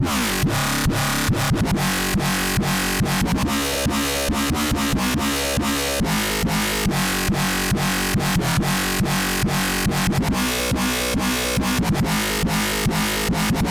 Tag: 150 bpm Hardcore Loops Synth Loops 2.15 MB wav Key : Unknown